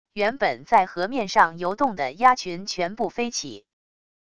原本在河面上游动的鸭群全部飞起wav音频